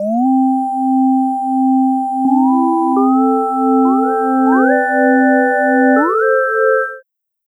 Track 16 - Synth 02.wav